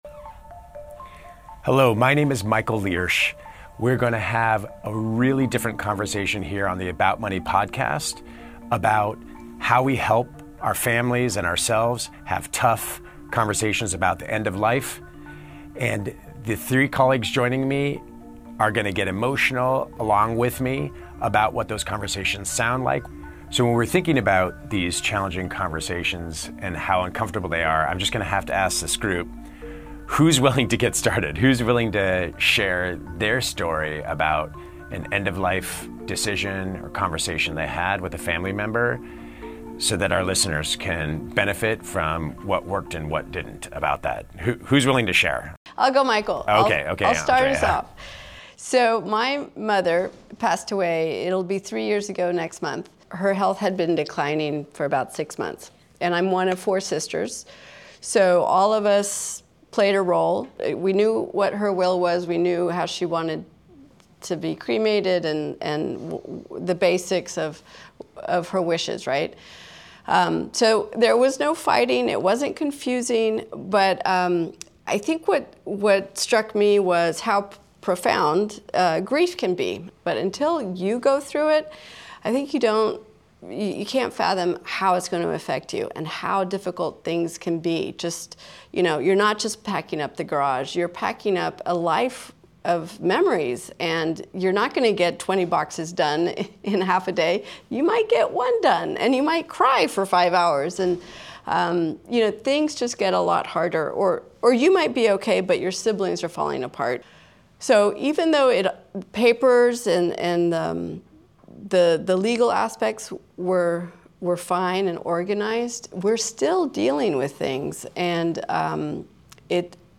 Hear how four Wells Fargo employees discussed difficult topics with aging parents and siblings and reached resolution.